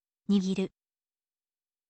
nigiru